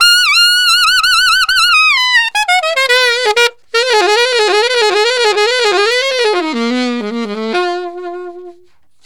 Alto One Shot in F 01.wav